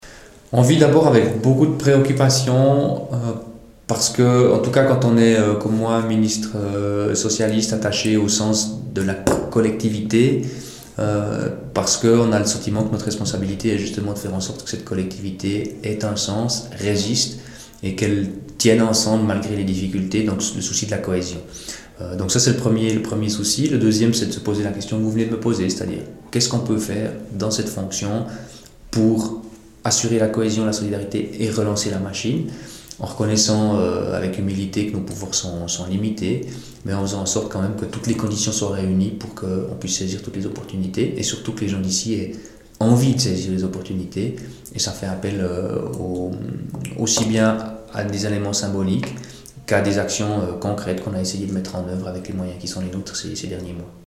Laurent Kurth, conseiller communal en charge de l’économie et de l’urbanisme de La Chaux-de-Fonds